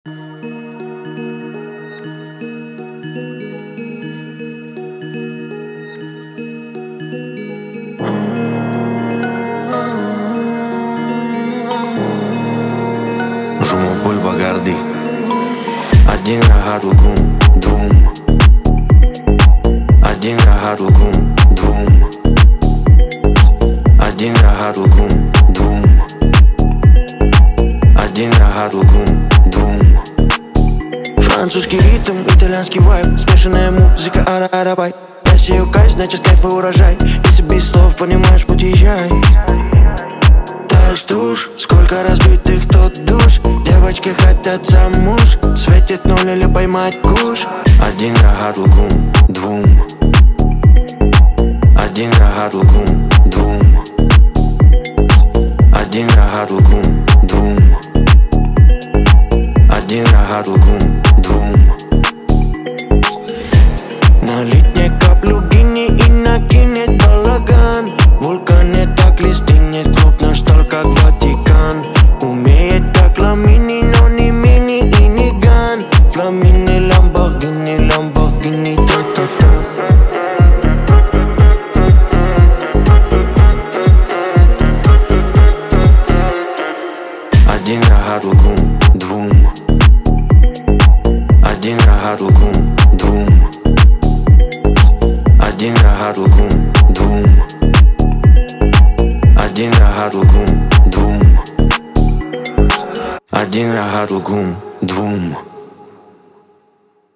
Энергичный и ритмичный трек
смешивая французский ритм и итальянский вайб.